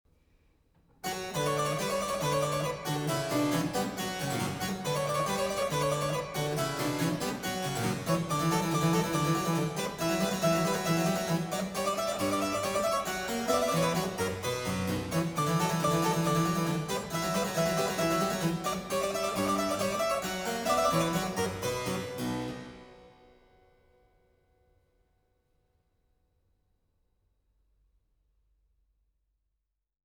Tendrement